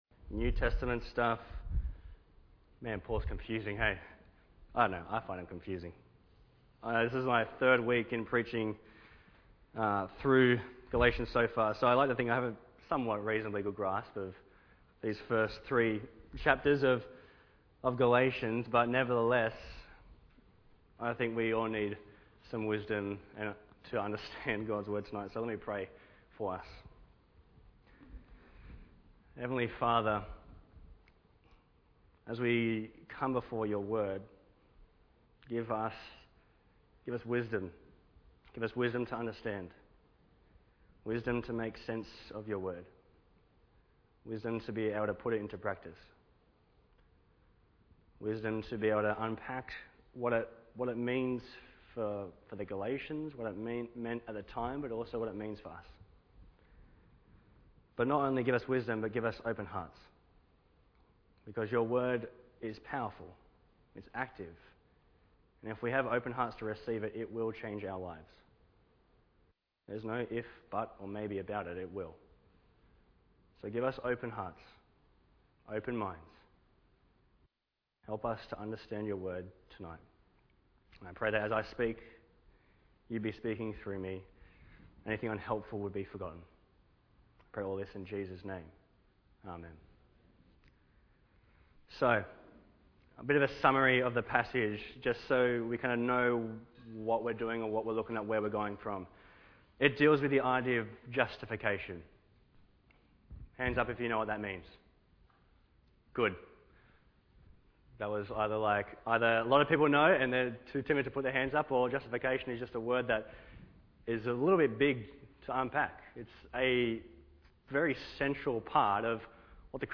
Bible Text: Galatians 2:11-21 | Preacher